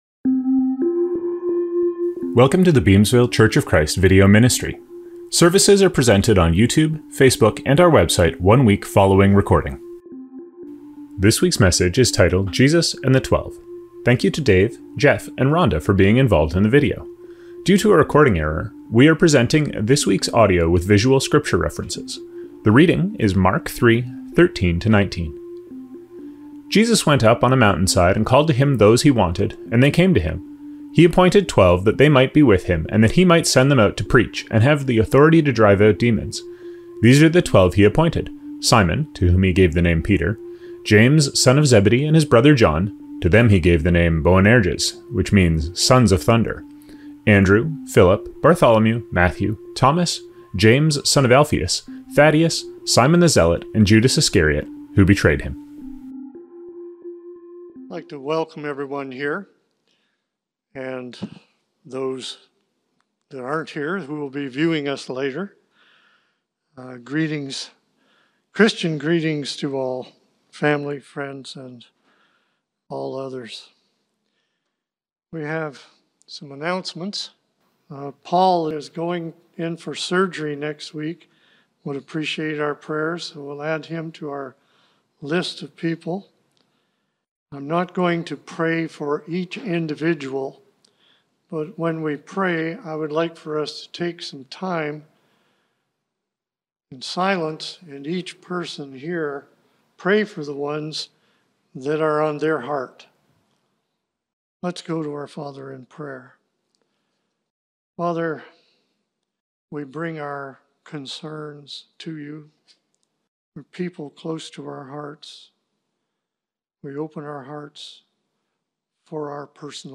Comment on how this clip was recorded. Due to a recording error, we are presenting this week's audio with visual scripture references.